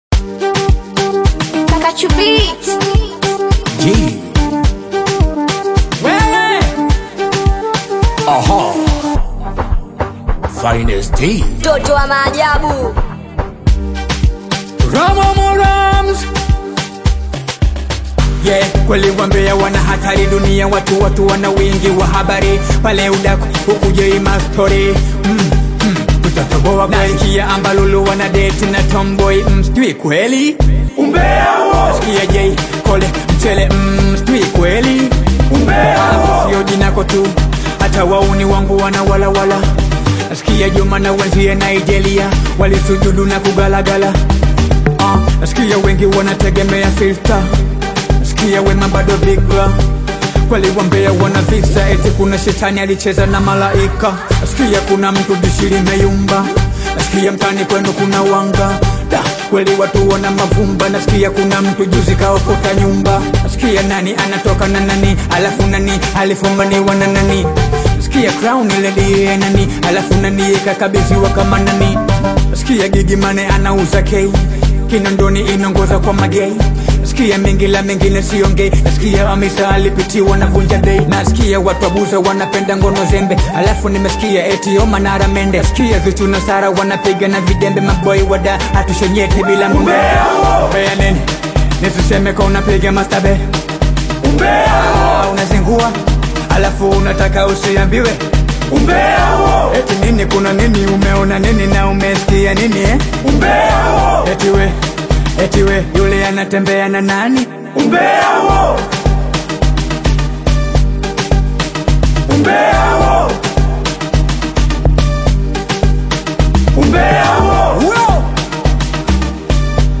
vibrant Afro-beat/Singeli single
Genre: Singeli